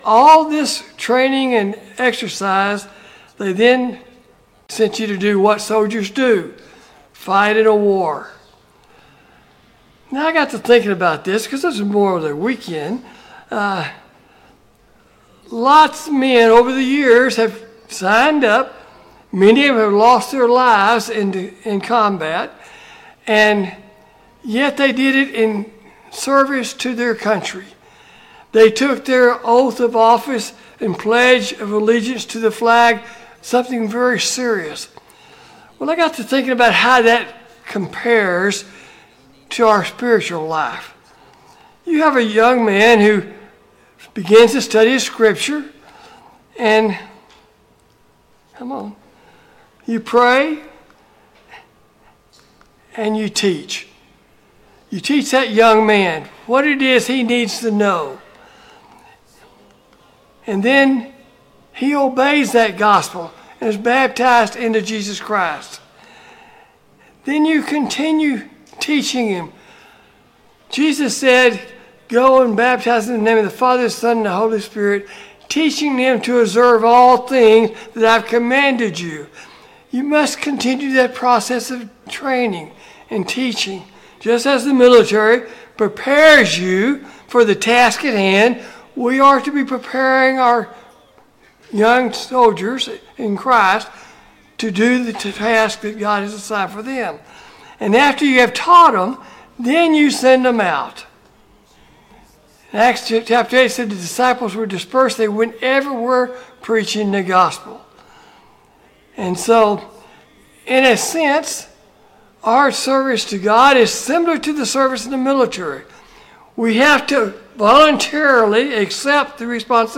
Sunday Morning Bible Class Topics: Biblical Authority